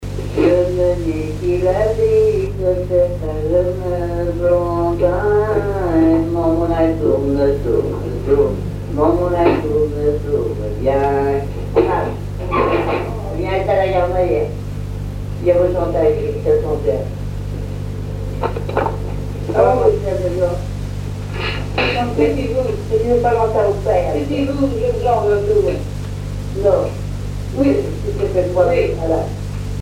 Mémoires et Patrimoines vivants - RaddO est une base de données d'archives iconographiques et sonores.
Chansons populaires
Pièce musicale inédite